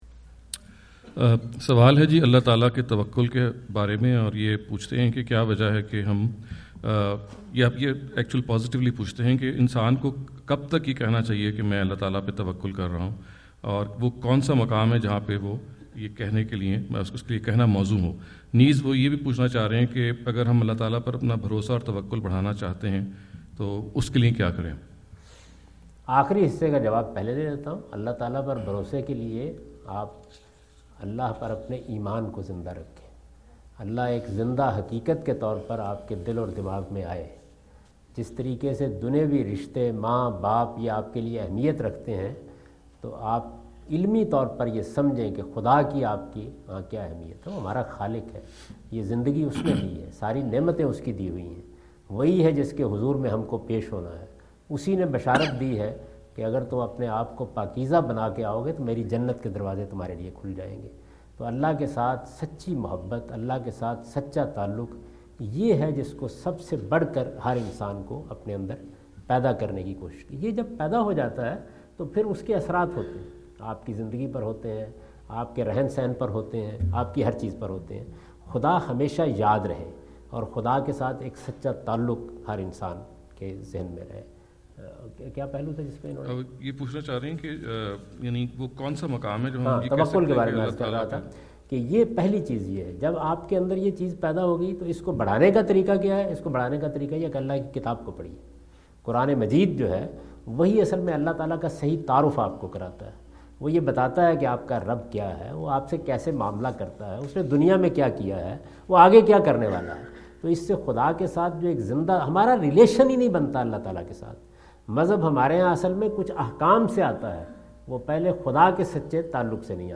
Javed Ahmad Ghamidi answer the question about "How to Create and Improve Trust in God" During his US visit in Dallas on October 08,2017.
جاوید احمد غامدی اپنے دورہ امریکہ2017 کے دوران ڈیلس میں "خدا پر توکل پیدا کرنا اور اس میں اضافہ کرنا" سے متعلق ایک سوال کا جواب دے رہے ہیں۔